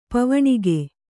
♪ pavaṇige